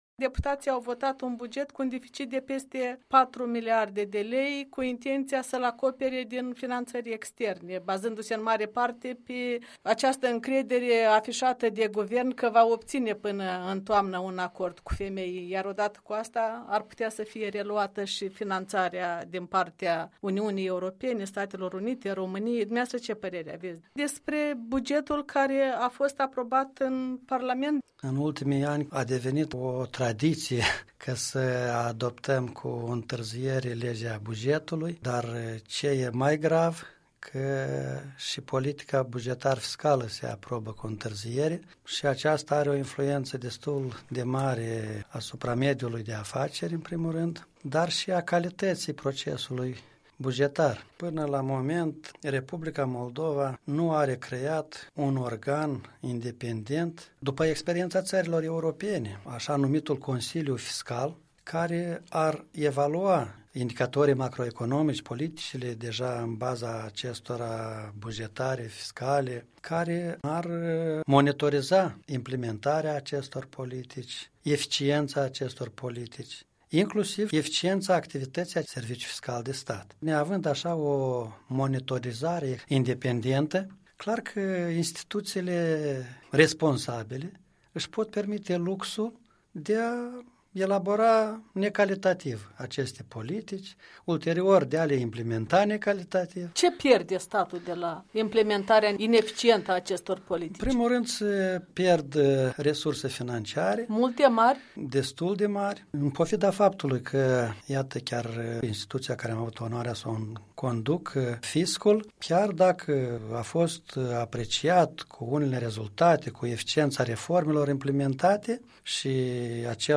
Interviu cu Ion Prisăcaru